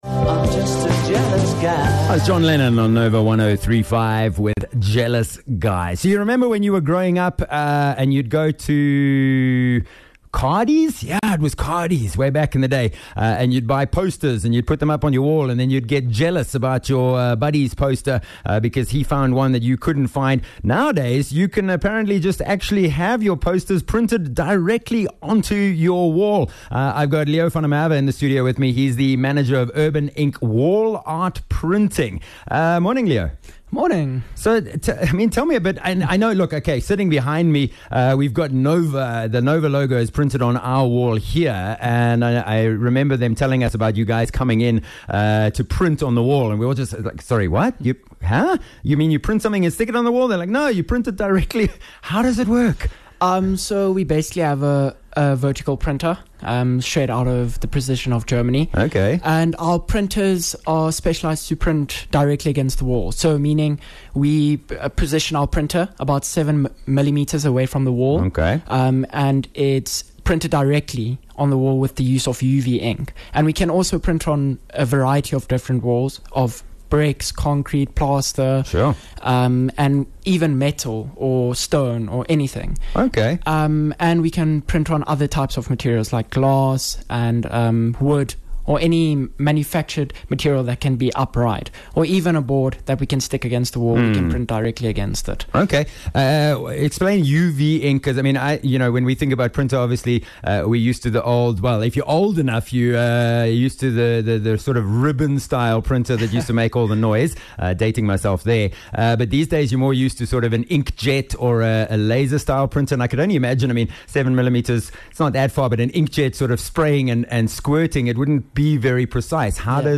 20 Jan Urban Ink Wall Art Printing Interview